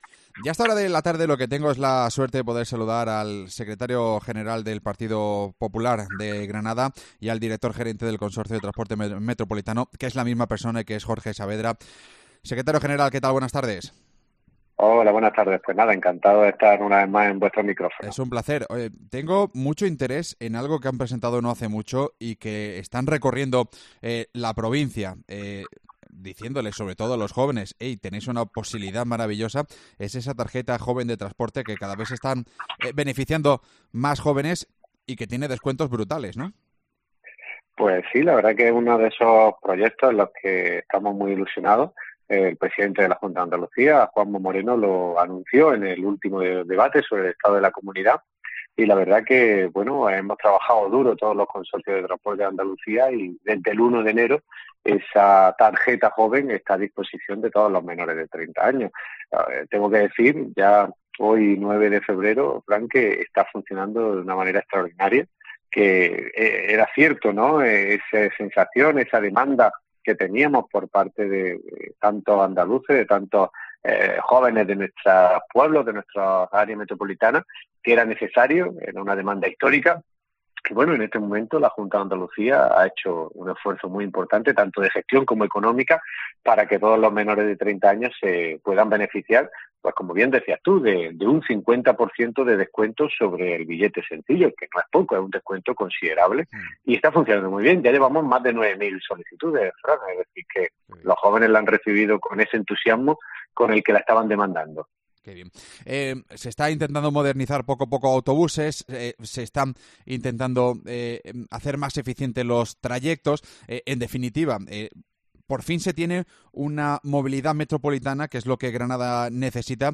AUDIO: El secretario general de los populares granadinos y director gerente del consorcio de transportes metropolitano ha repasado en COPE la...